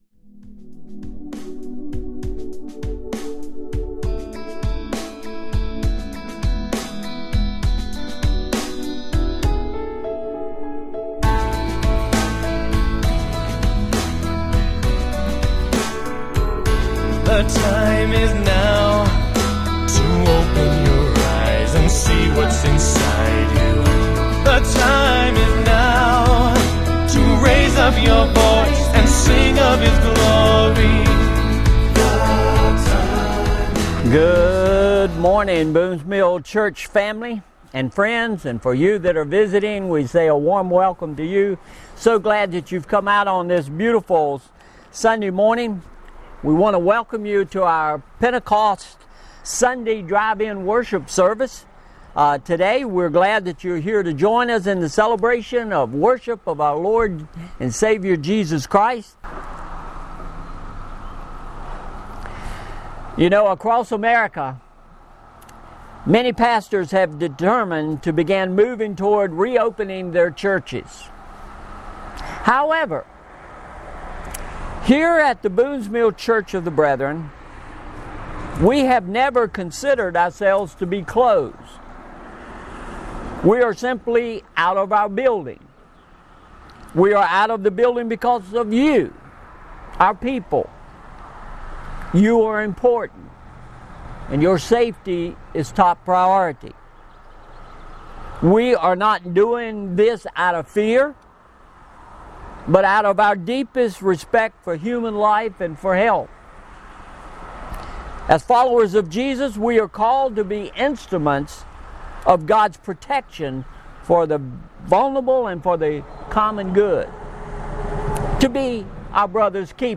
MP3 Notes Discussion Sermons in this Series Sermon Series Lord, We Need A Miracle – Part 6 "Before We Sink!"